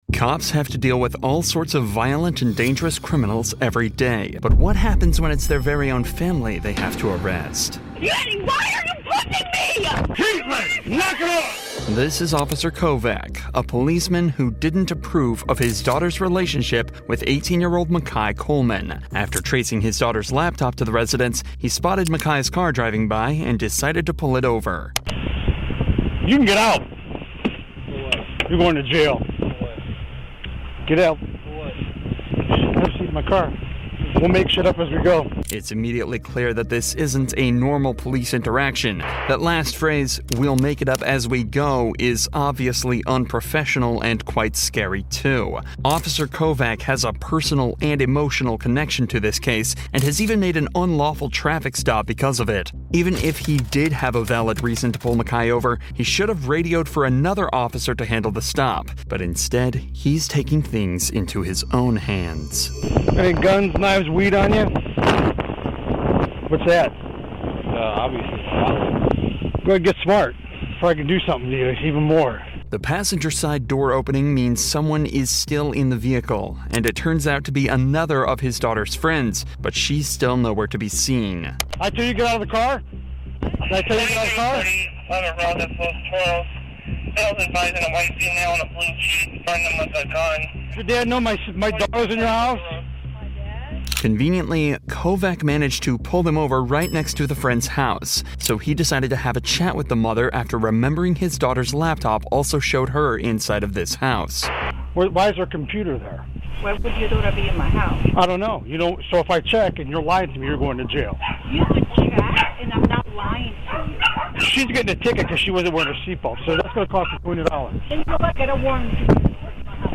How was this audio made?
All advertisements are strategically placed only at the beginning of each episode, ensuring you can immerse yourself fully in every investigation, every revelation, and every emotional moment without advertising cuts disrupting the flow of our true crime storytelling.